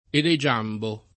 elegiambo [ ele J# mbo ]